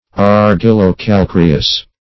argillo-calcareous.mp3